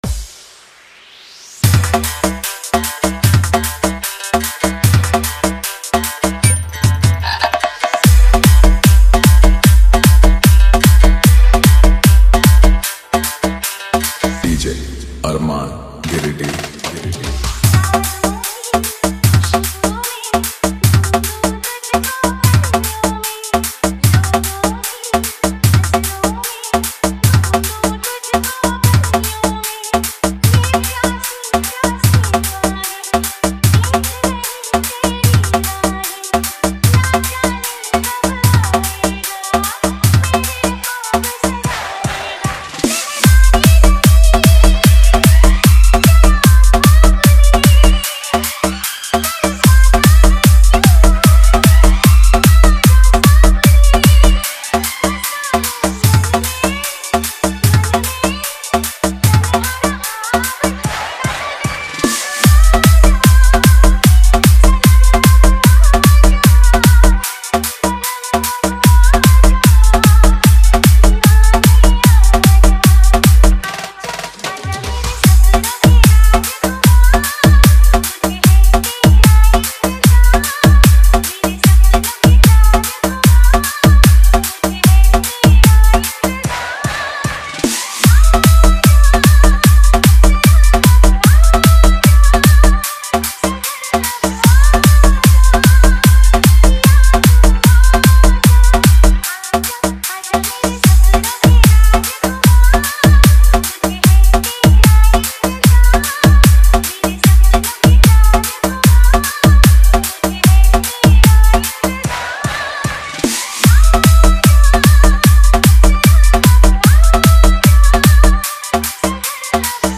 Category : Old Is Gold Remix Song